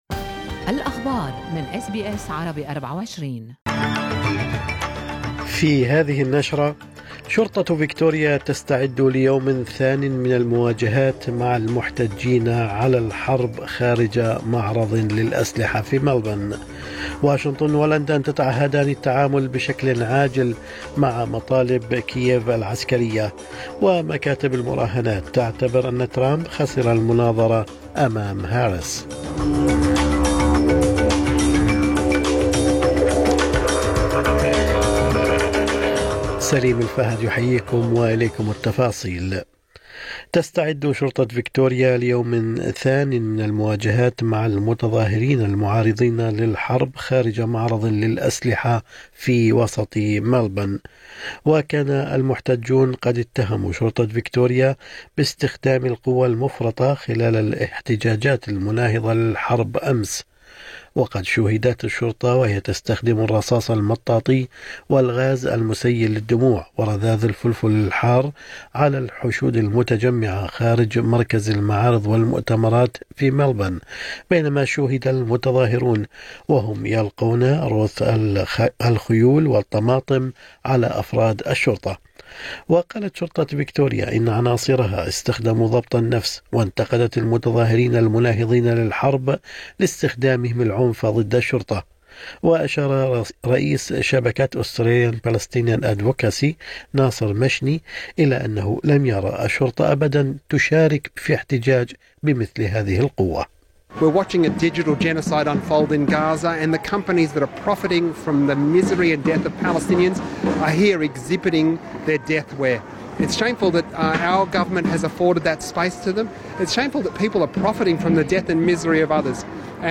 نشرة أخبار الصباح 12/9/2024